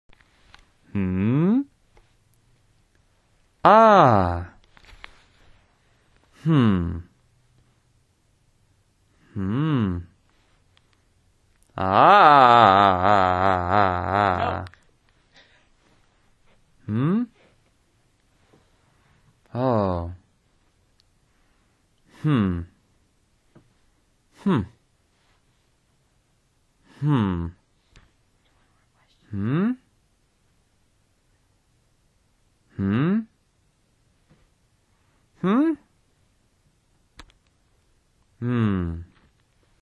SFX嗯(Hmm Ahh)音效下载
SFX音效